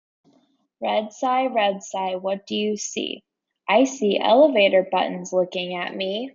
A repetitive, rhythmic chant follows Red Cy as it sees fresh white snow, elevator buttons, and Cy Ride, building to a final cumulative line.